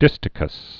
(dĭstĭ-kəs)